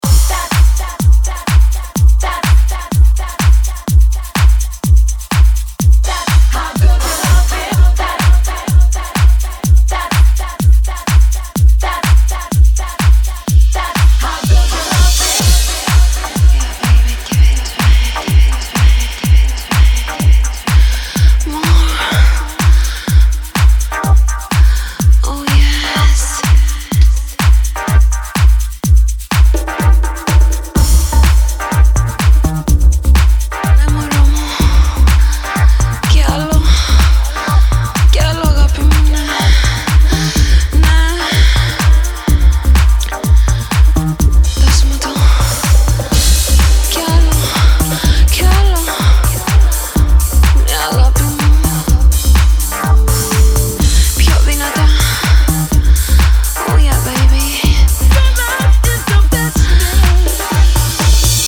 • Funky House